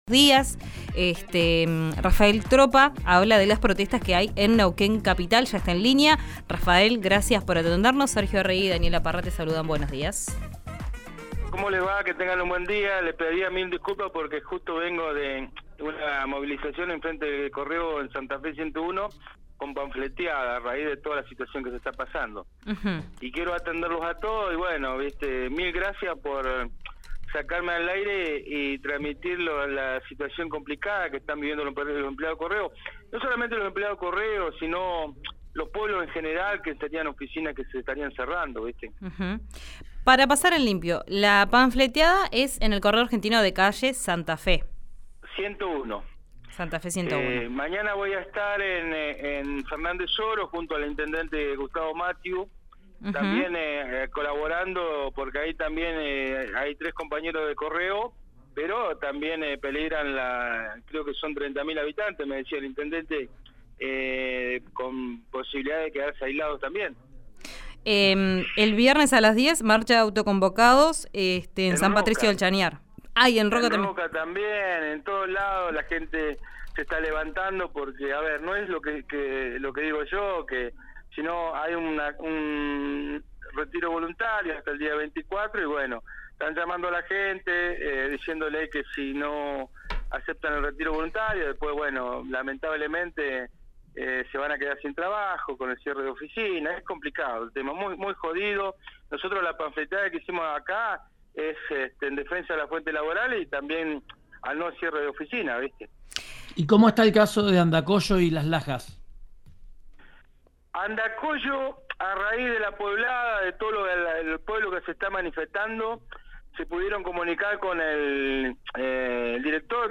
En diálogo con RÍO NEGRO RADIO, dijo que los que han adherido al retiro voluntario son alrededor de 70.